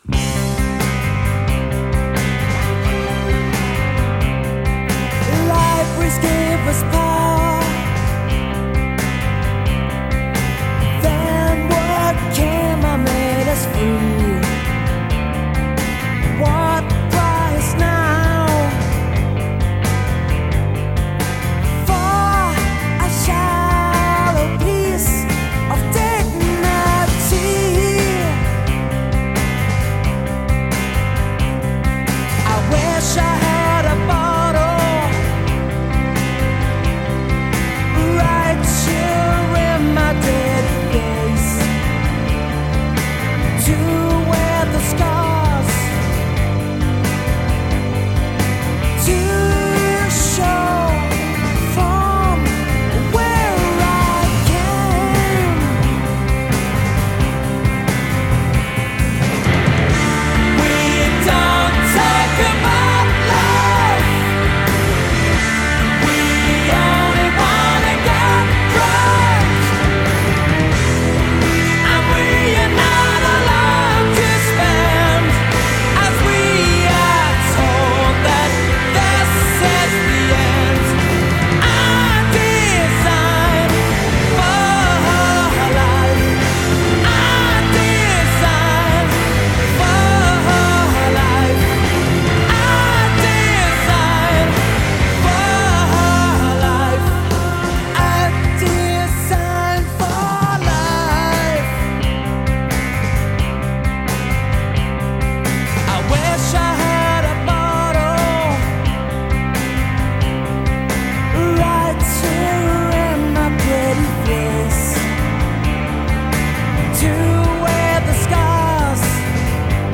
Probably their most perfect pop song.
Rock, pop, sixties strings. Sweeps you along.